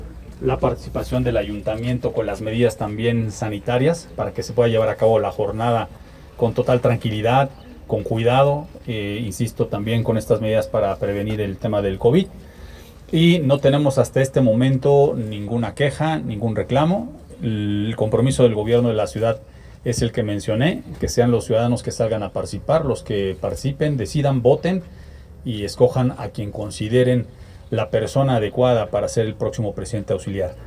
En entrevista, Rivera Pérez detalló que, a través de la Secretaría de Gobernación Municipal y de las diversas dependencias del Gobierno de la Ciudad, permanecerá una constante coordinación a fin de que el proceso se lleve en calma.